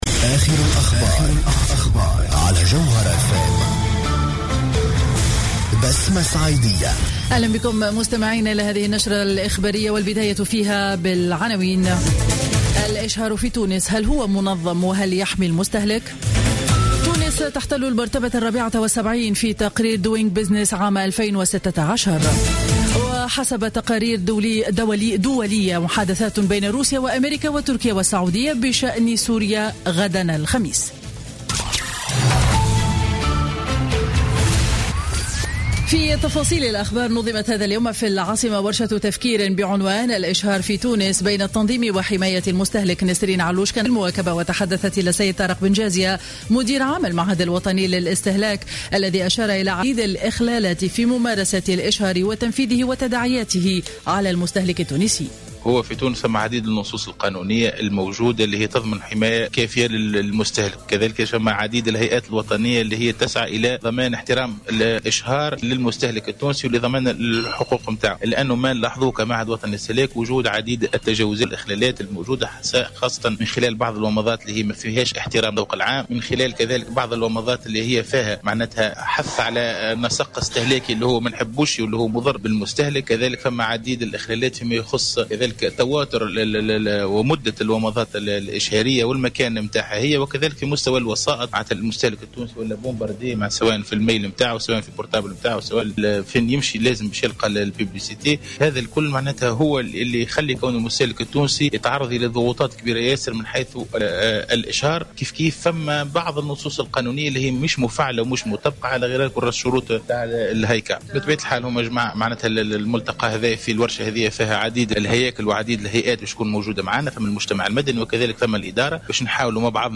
نشرة أخبار منتصف النهار ليوم الأربعاء 28 أكتوبر 2015